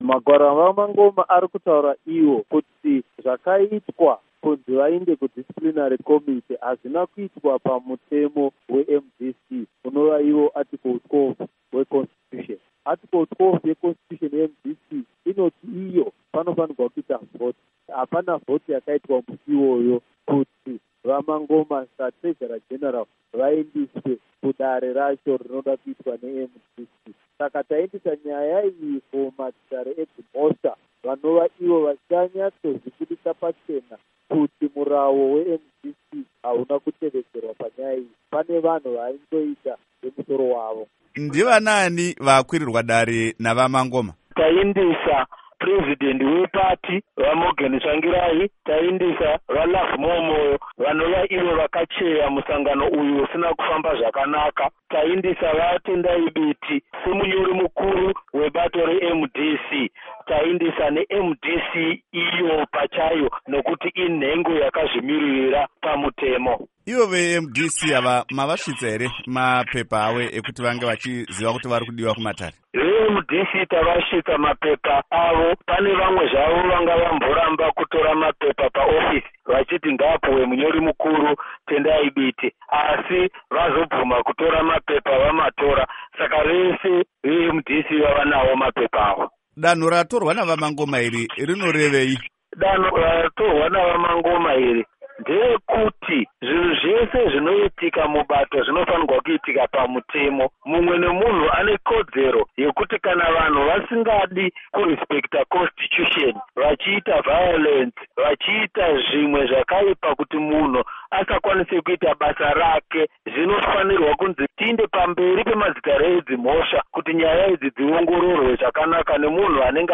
Hurukuro naVaJacob Mafume